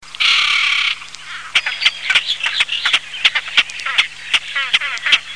Grèbe en plumage hivernal
grebe.mp3